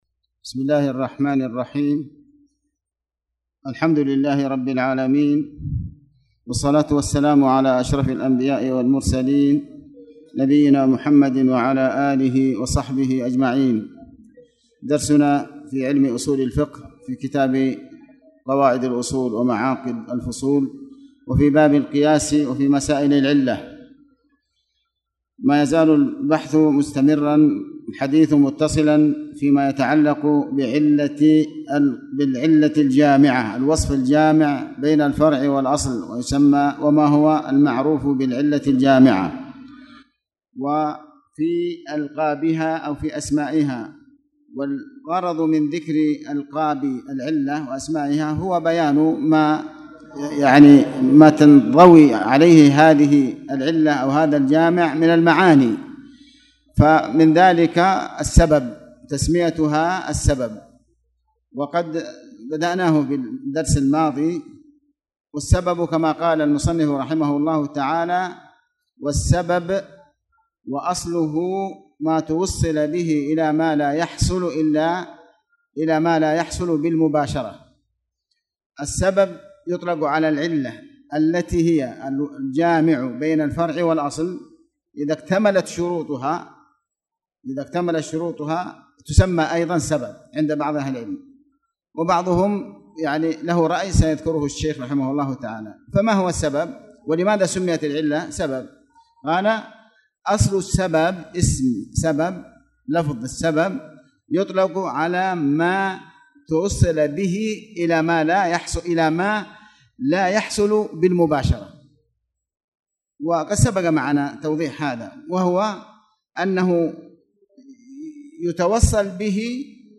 تاريخ النشر ٨ ربيع الأول ١٤٣٨ هـ المكان: المسجد الحرام الشيخ: علي بن عباس الحكمي علي بن عباس الحكمي مسائل العلة